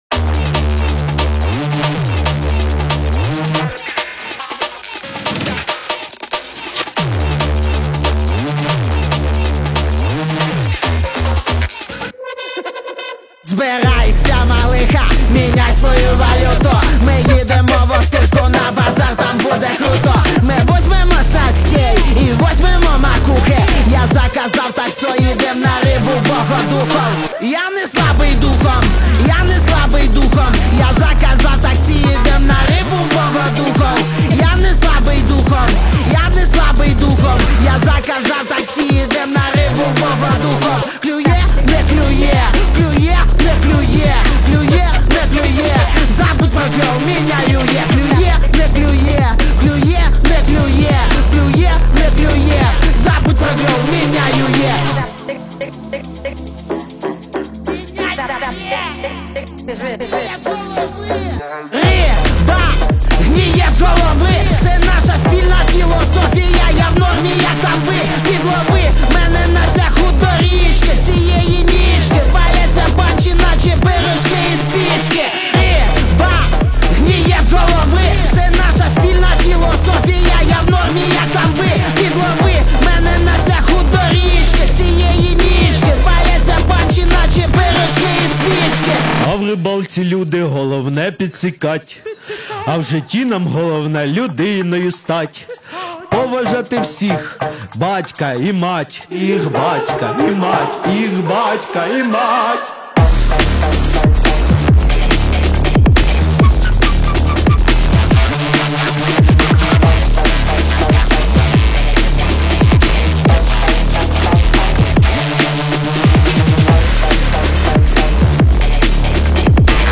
• Жанр:Танцювальна